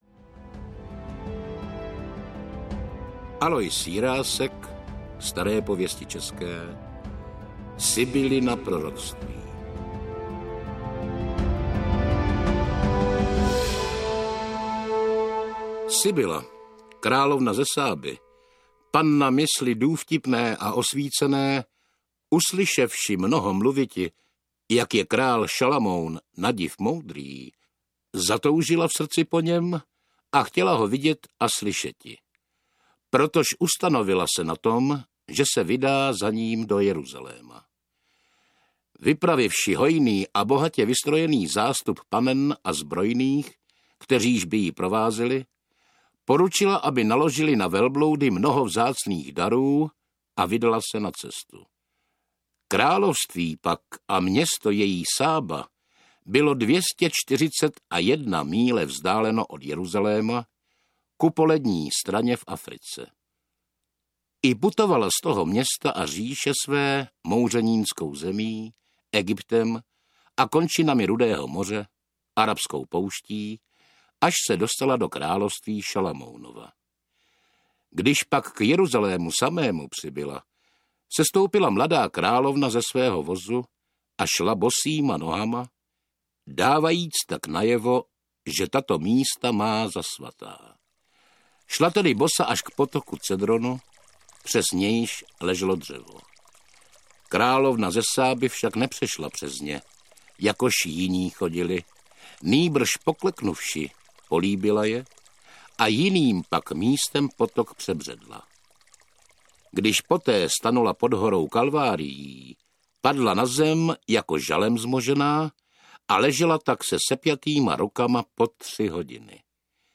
Staré pověsti české audiokniha
Toto je nejprestižnější a nejpůsobivější zvukové zpracování našich národních bájí.
Ukázka z knihy